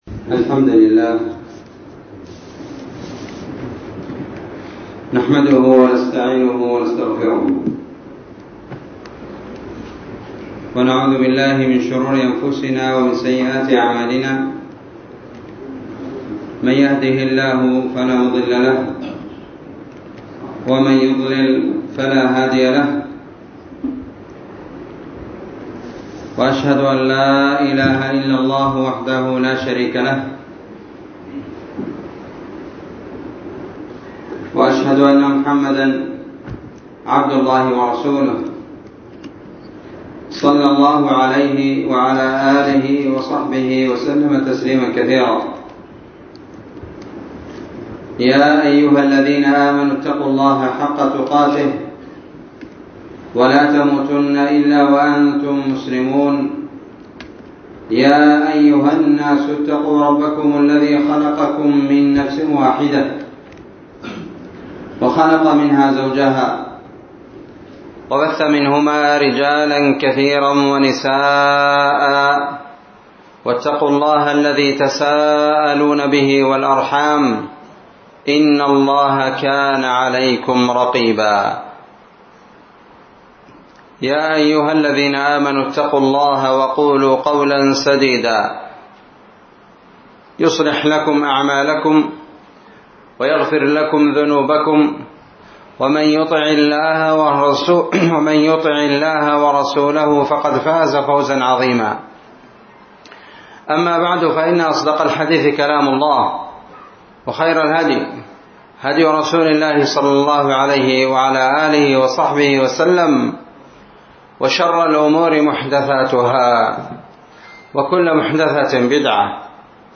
محاضرة بعنوان نعمة الإسلام والسنة والعلم النافع ٣ جمادى الآخرة ١٤٤٤